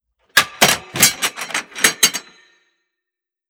Misc (Ammo Boxes, Holsters, Etc)
Ammo Crate Epic 001.wav